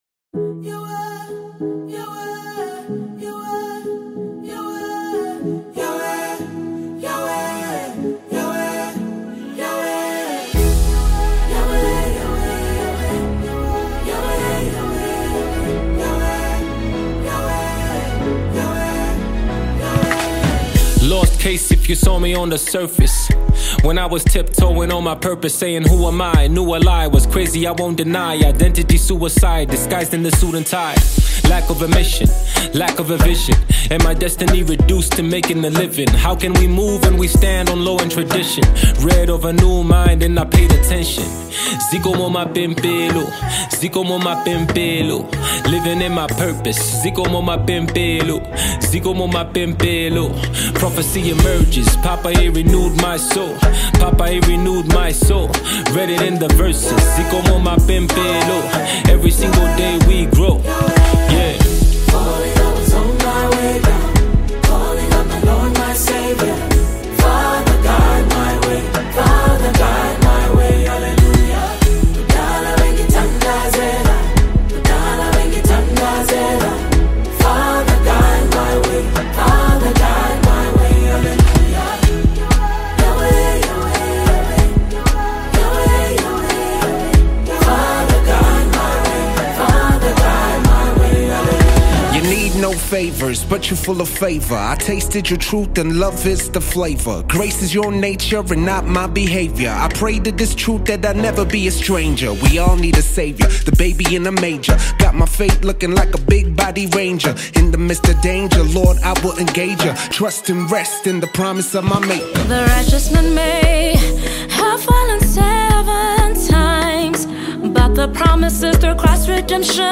worship anthem